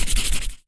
Sfx Crayon Sound Effect
sfx-crayon-1.mp3